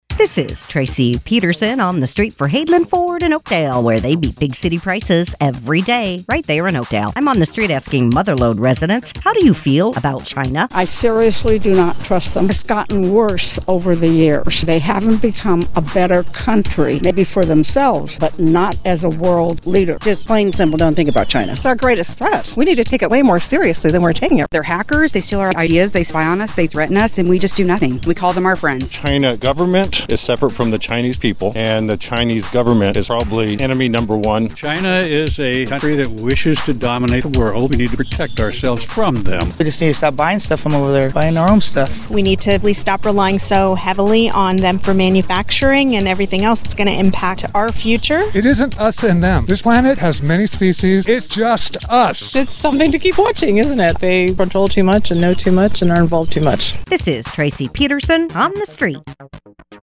asks Mother Lode residents